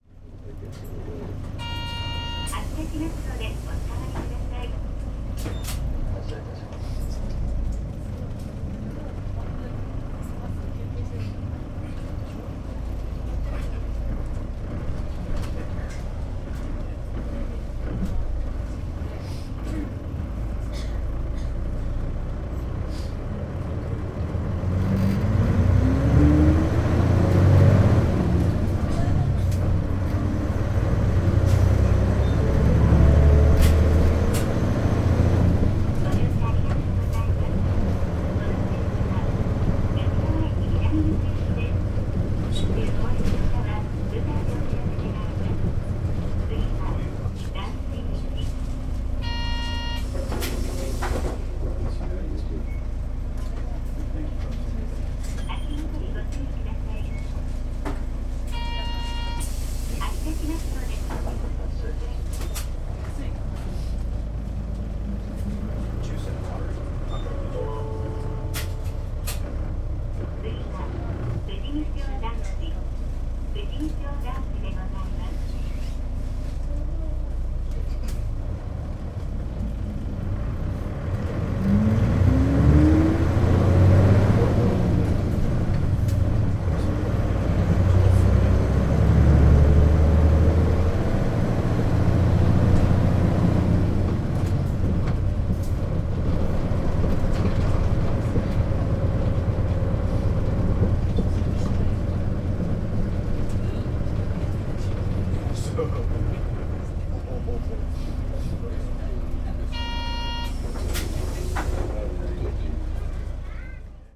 全国路線バス走行音立川バス
エンジン音はOD付の標準的なもので、適度に引っ張っていました。話し声が多めですがご容赦を・・・。